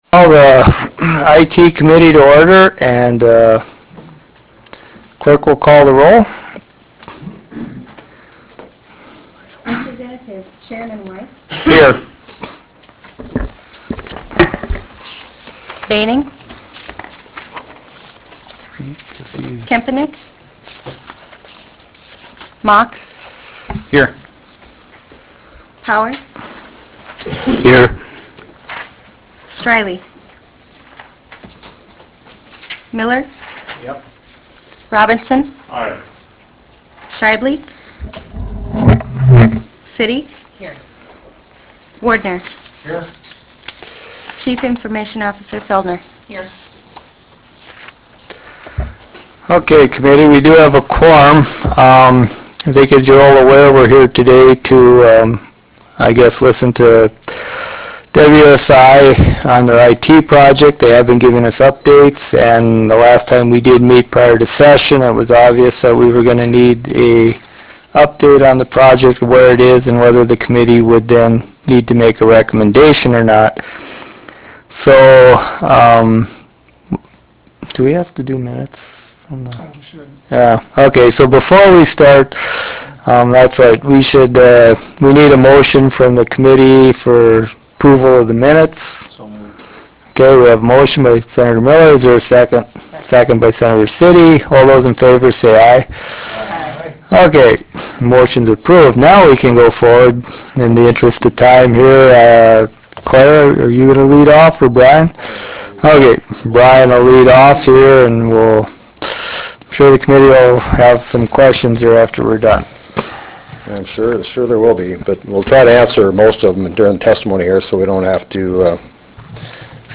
Fort Union Room State Capitol Bismarck, ND United States